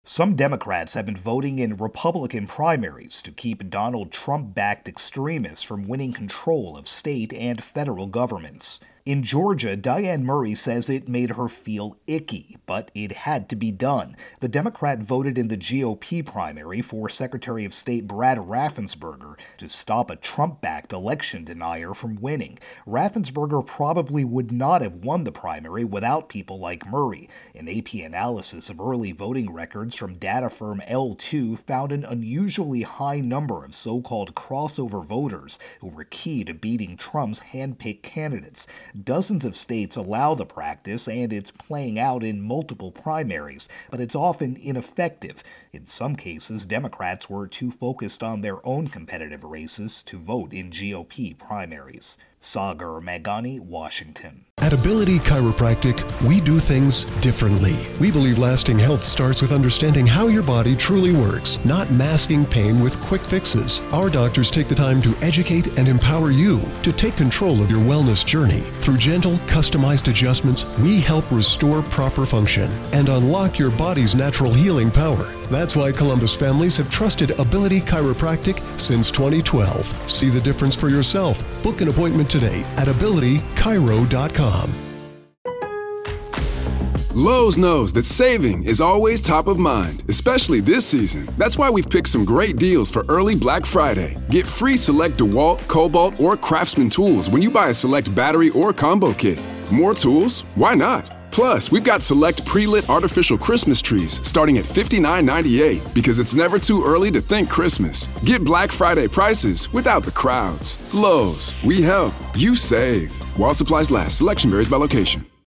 Election 2022-Crossover Voters intro and voicer.